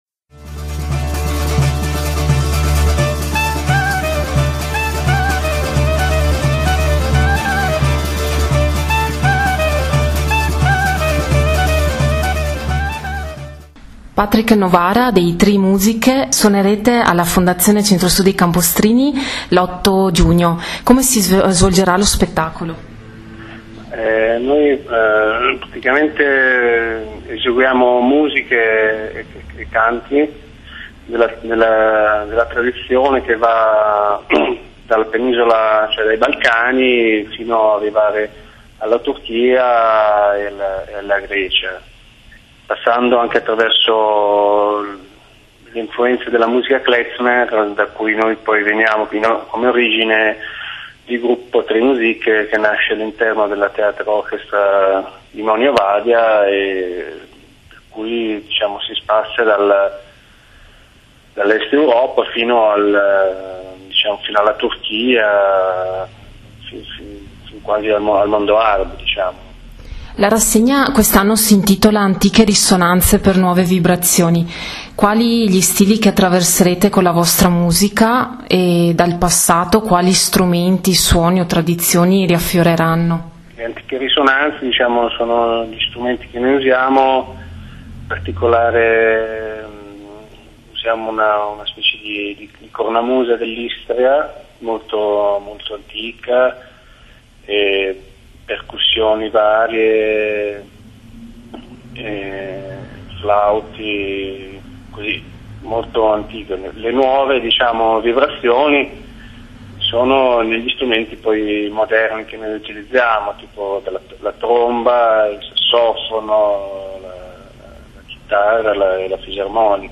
Intervista audio